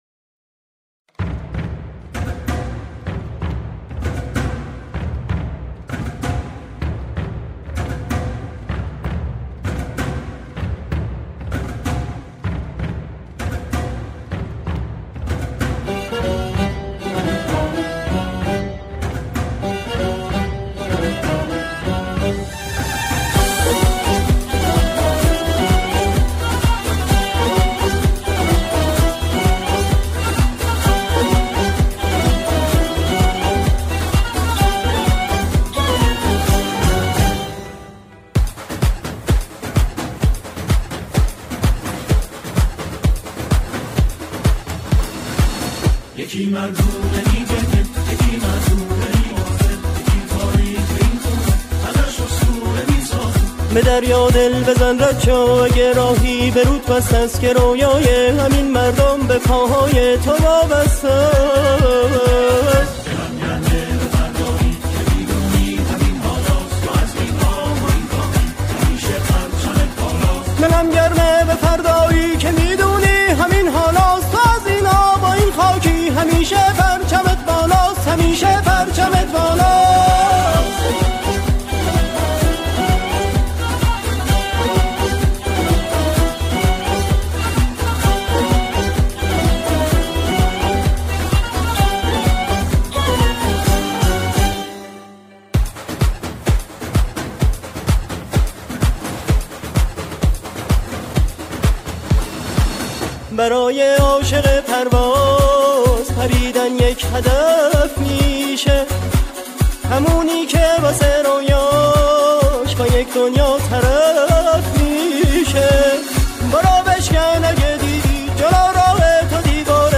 سرودهای ورزشی
او در این قطعه، شعری ورزشی را می‌خواند.